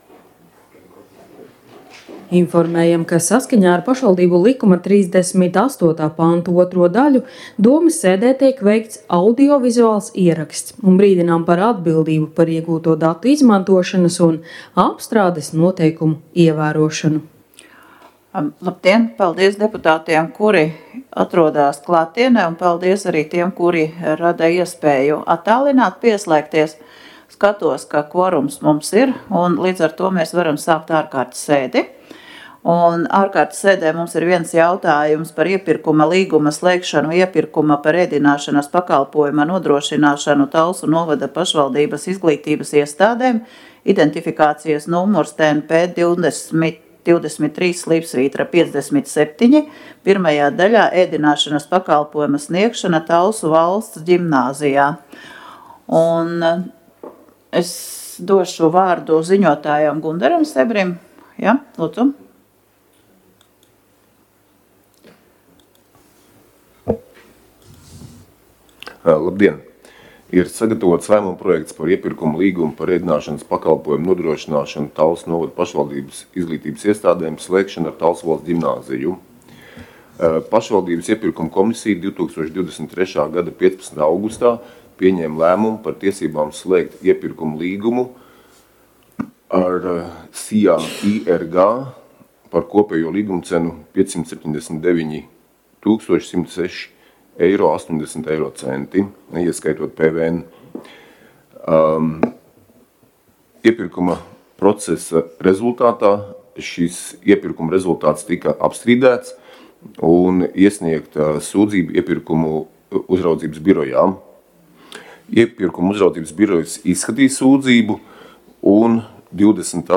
Domes sēdes audio